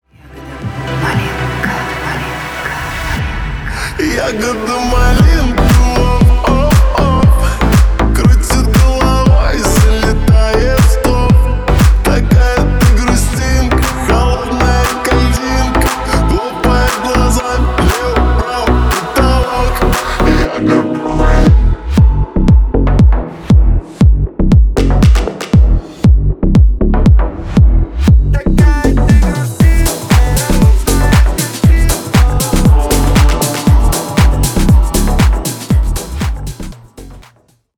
• Качество: 320 kbps, Stereo
Ремикс
Поп Музыка
Танцевальные